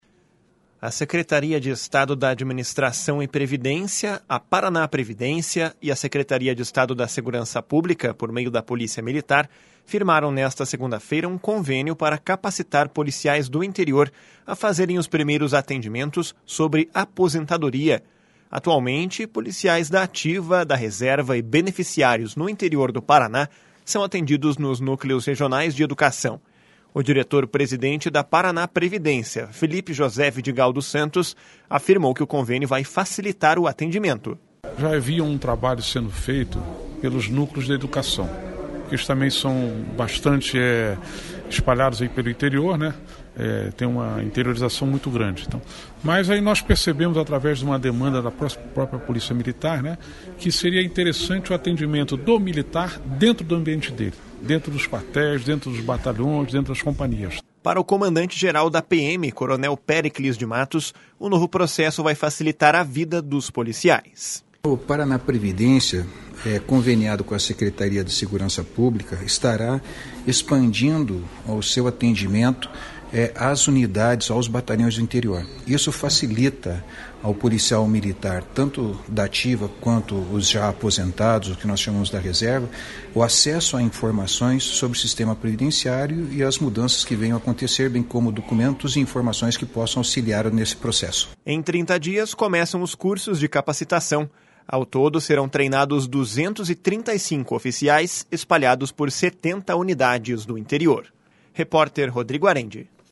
O diretor-presidente da ParanáPrevidência, Felipe José Vidigal dos Santos, afirmou que o convênio vai facilitar o atendimento.
Para o comandante-geral da PM, coronel Péricles de Matos, o novo processo vai facilitar a vida dos policiais.